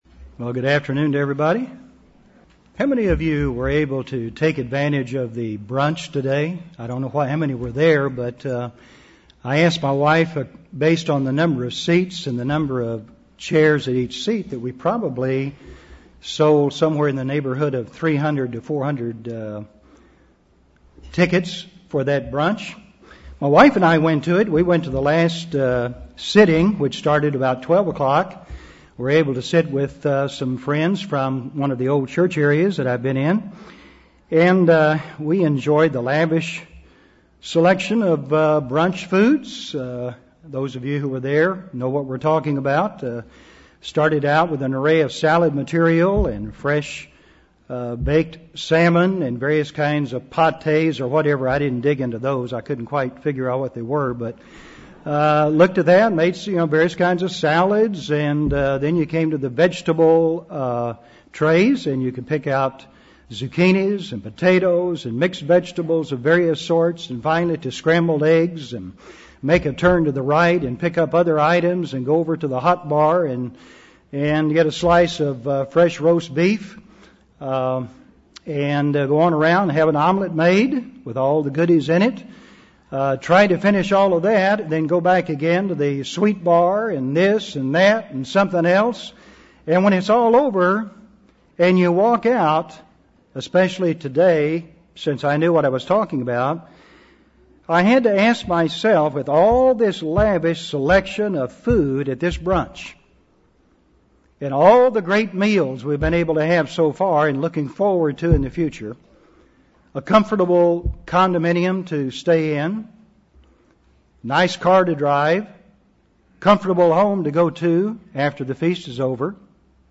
This sermon was given at the Jekyll Island, Georgia 2007 Feast site.